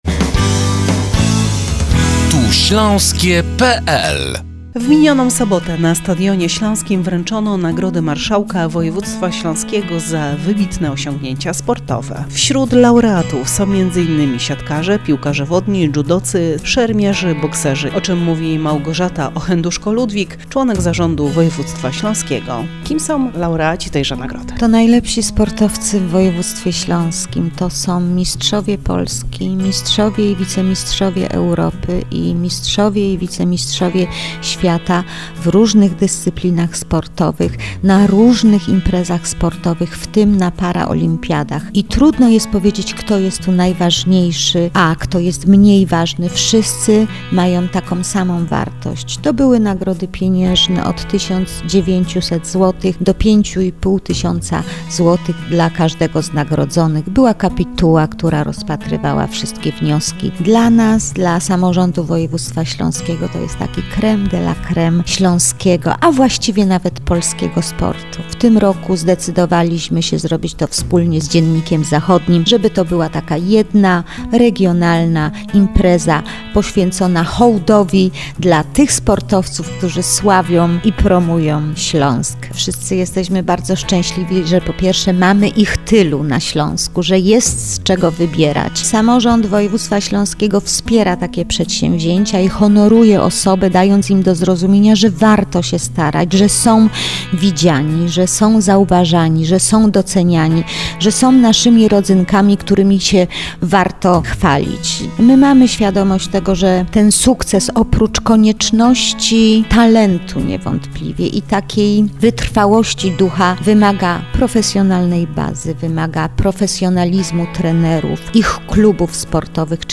Nagrody Marszałka za osiągnięcia sportowe - audycja Radia Piekary [MP3 5,0MB]